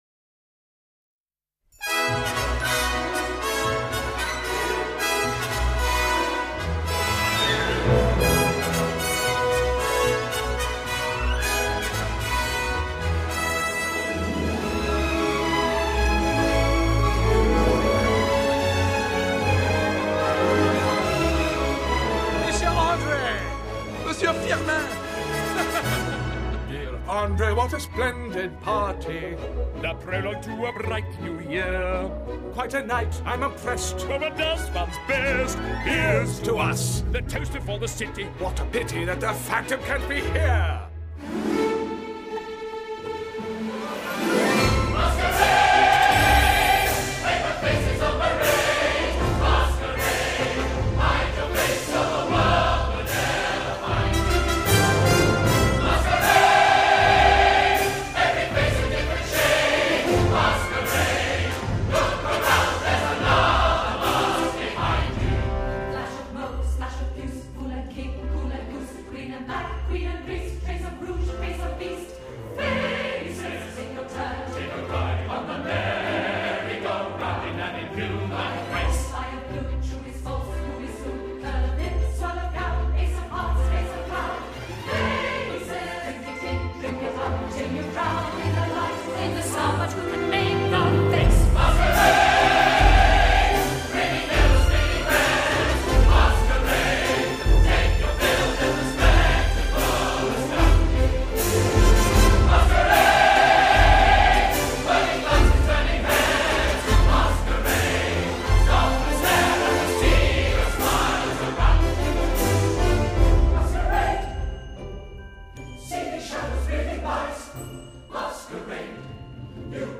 音乐类型：电影配乐
集合全好莱坞及百老汇最能歌善舞演员 亲自演唱